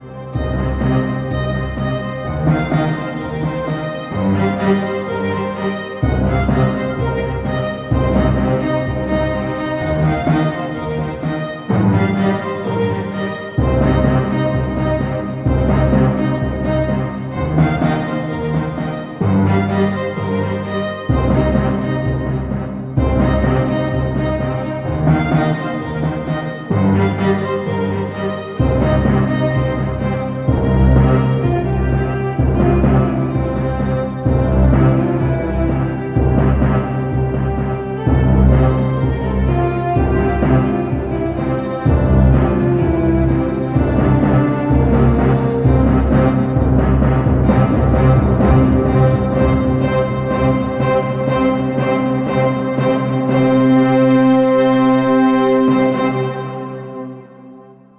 Epic Happy Soundtrack.